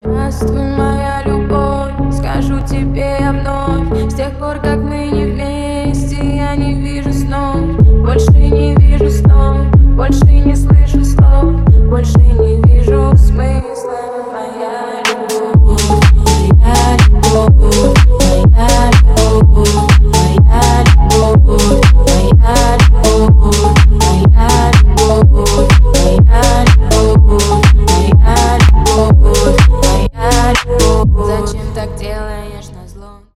поп
house , клубные